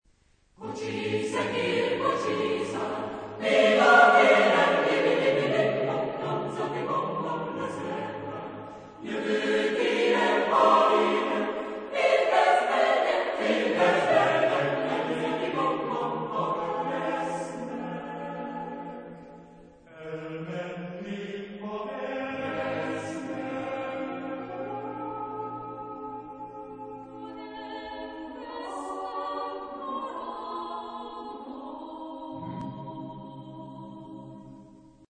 (Choeurs de chansons populaires)
Genre-Style-Forme : Populaire ; Chœur
Type de choeur : SATB  (4 voix mixtes )